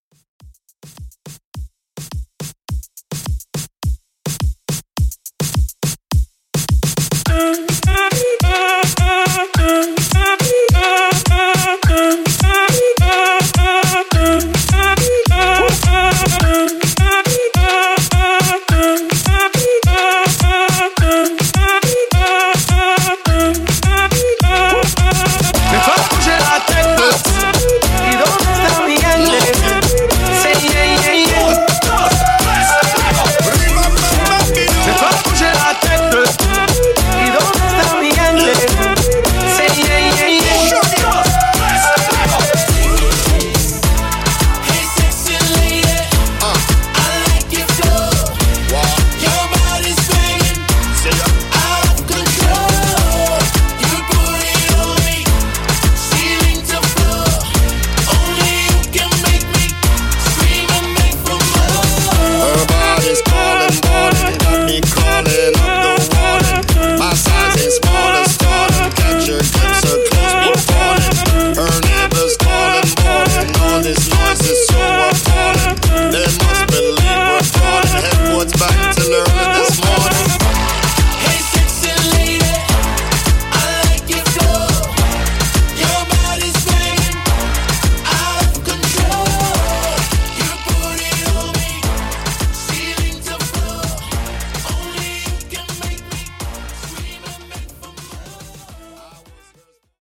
Mashup Moombah)Date Added